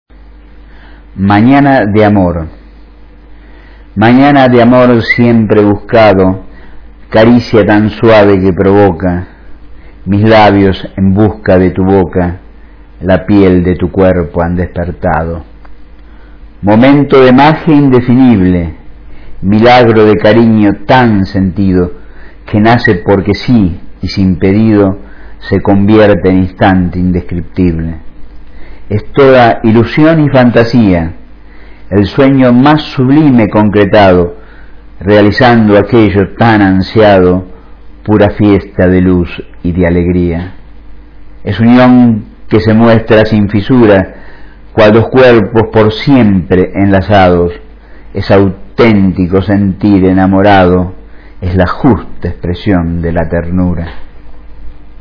Recitado por el autor (0:54", 213 KB)